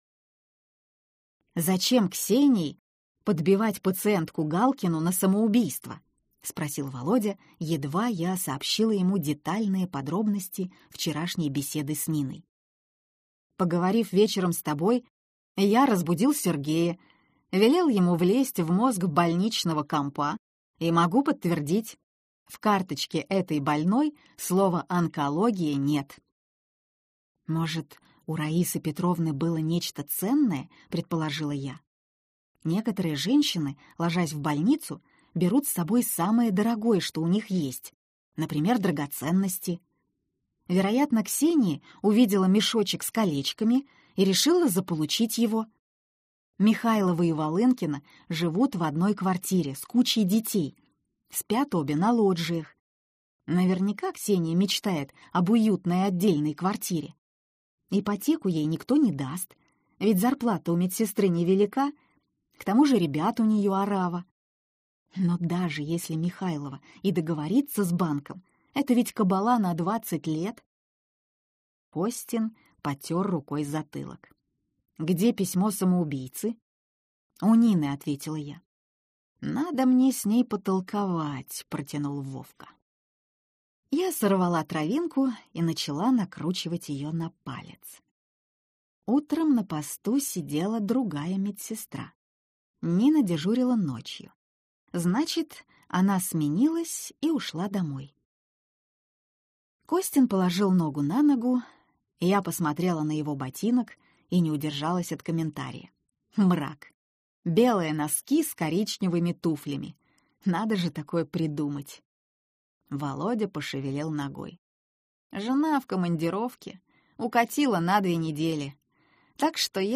Аудиокнига Имидж напрокат - купить, скачать и слушать онлайн | КнигоПоиск